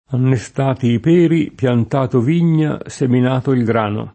innestare v. («trapiantare; inserire»); innesto [inn$Sto] — pop. tosc. annestare: annesto [ann$Sto]: annestati i peri, Piantato vigna, seminato il grano [